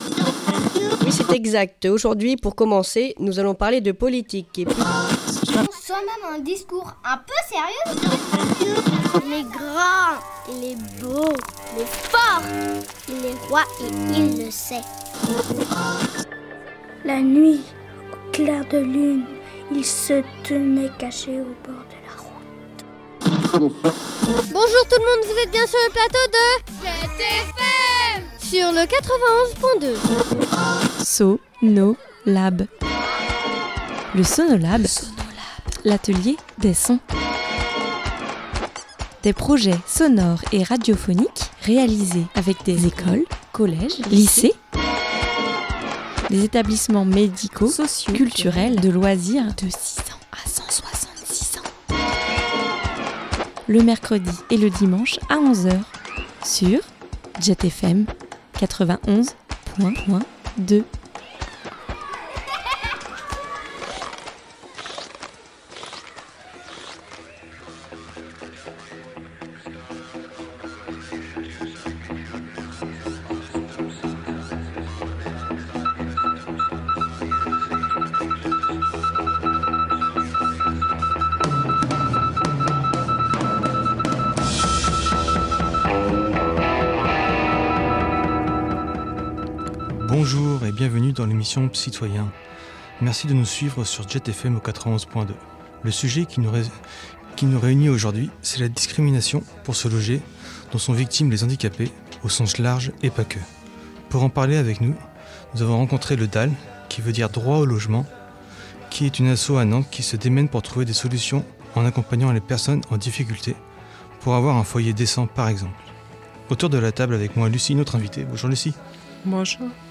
Un atelier radio en collaboration avec Jet a lieu tous les quinze jours, pour que les adhérents qui le souhaitent préparent cette émission. L’idée est d’apporter un regard et une parole différente à la maladie psychique.